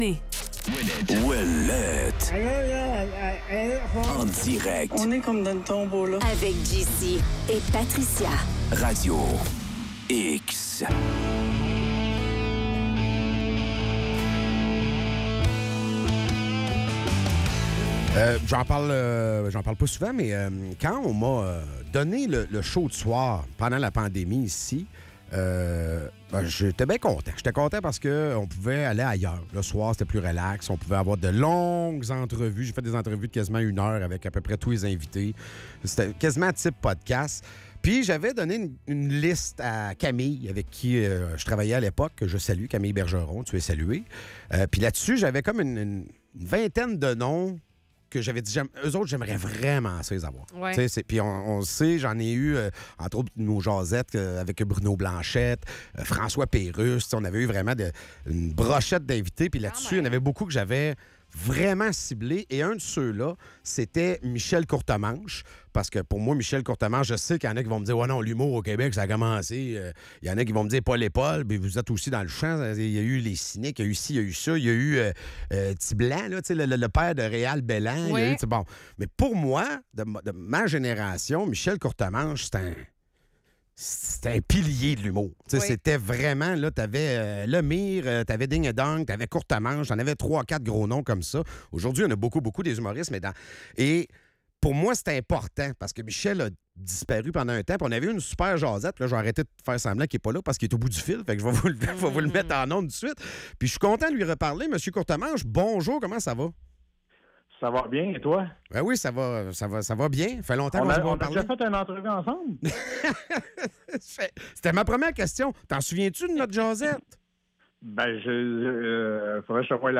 En entrevue, Michel Courtemanche.